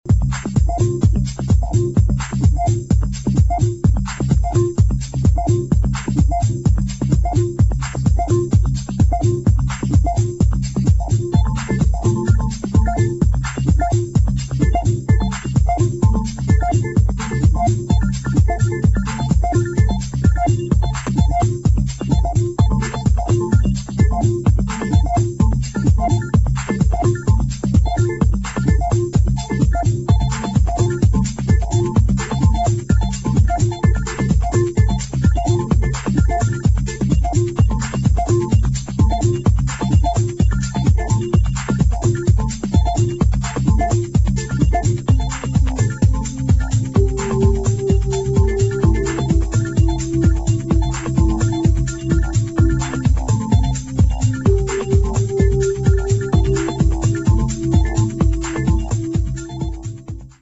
[ TECHNO | TECH HOUSE | MINIMAL ]